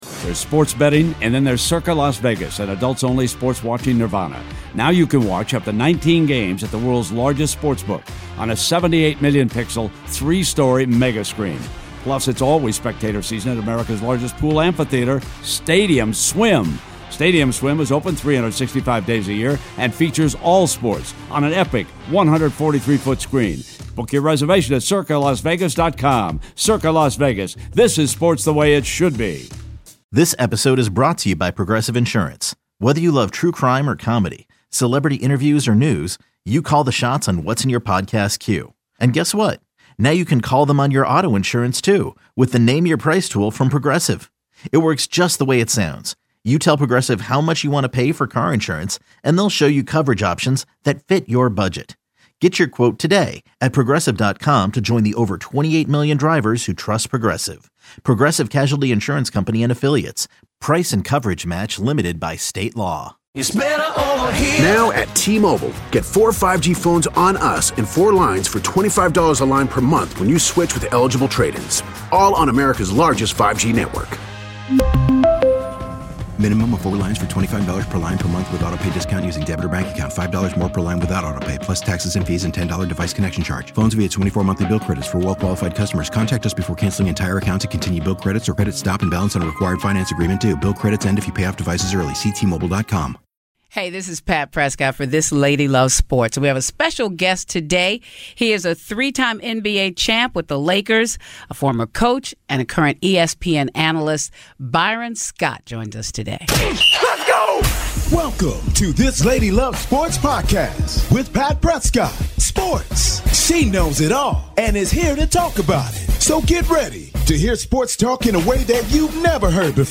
interviews Byron Scott